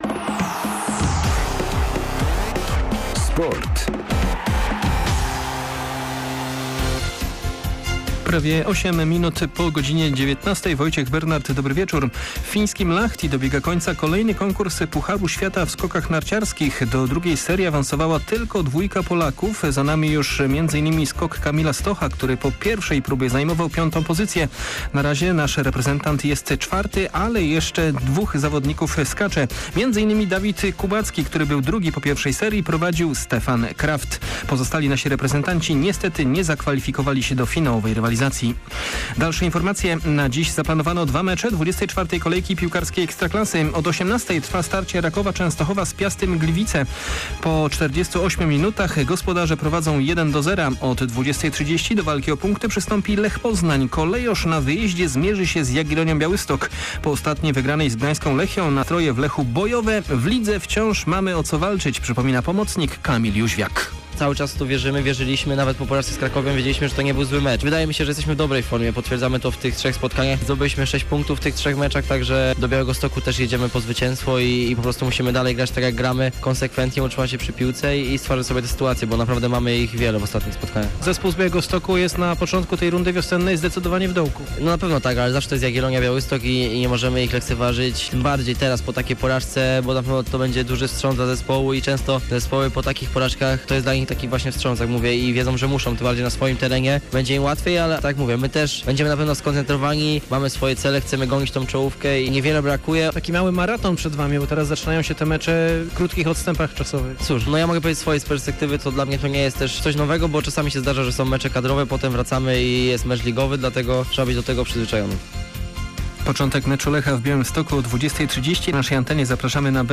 28.02. SERWIS SPORTOWY GODZ. 19:05